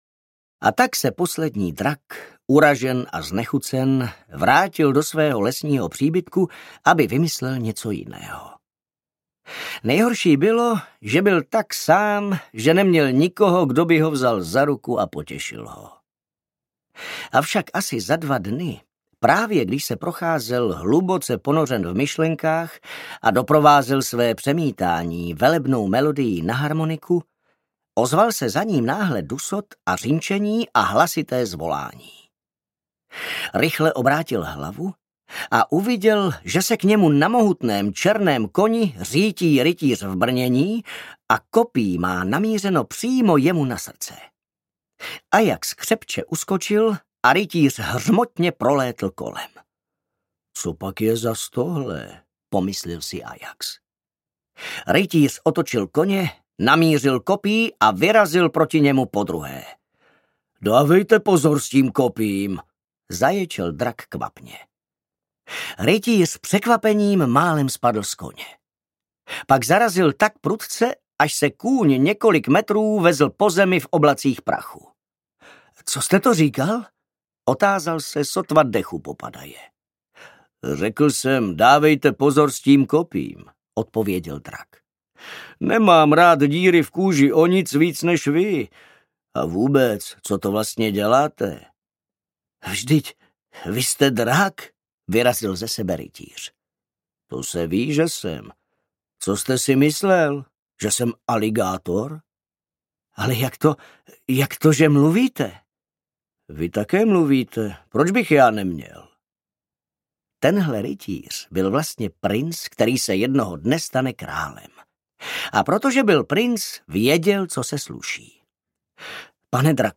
Poslední drak audiokniha
Ukázka z knihy